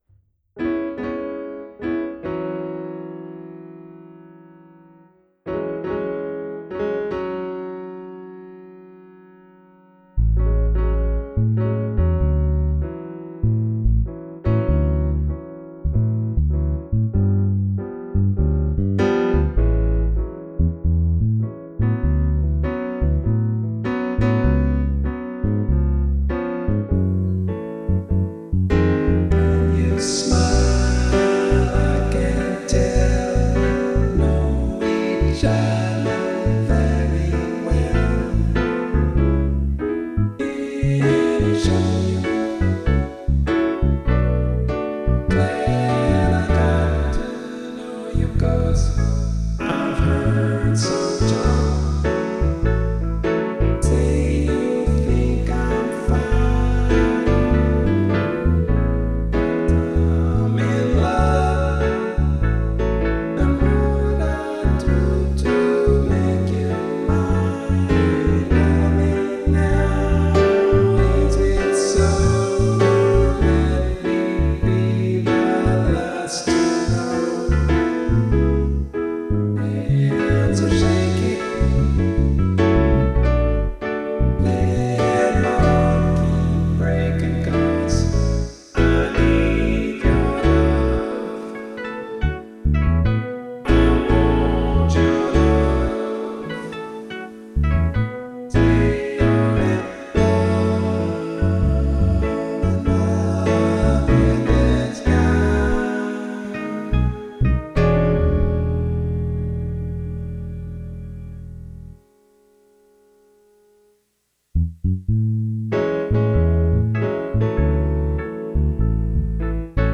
Singalong: no uke or lead vocal